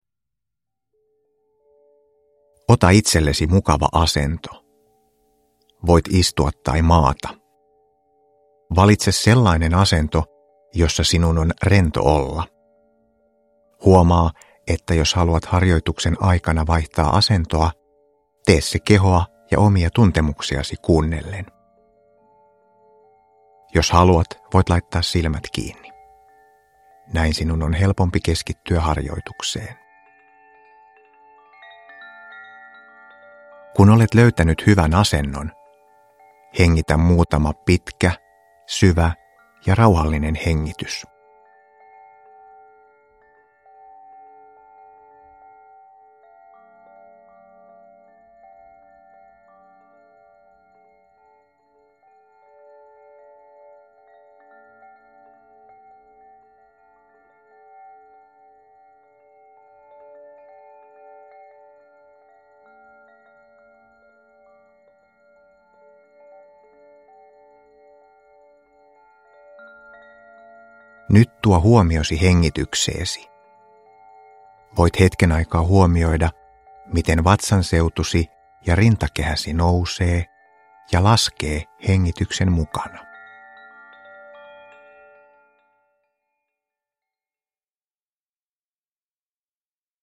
Rauhoita mielesi unille – Ljudbok – Laddas ner
Produkttyp: Digitala böcker